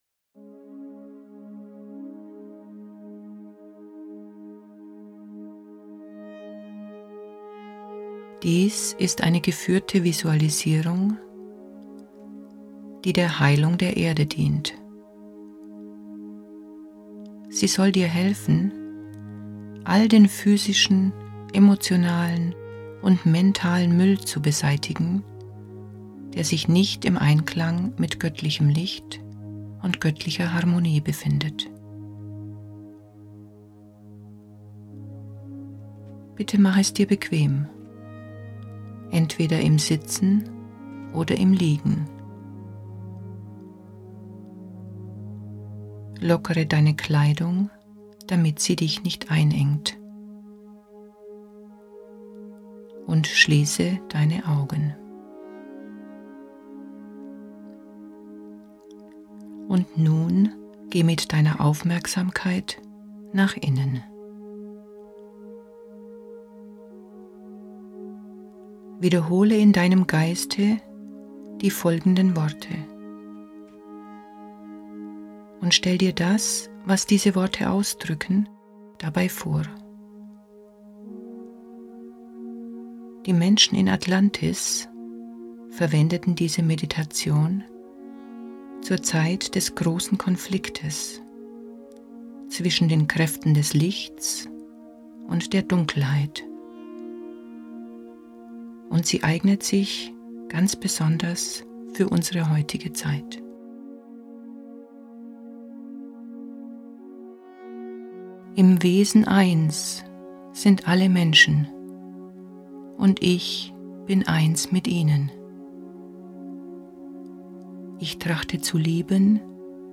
Geführte Visualisierung, die der Heilung der Erde dient.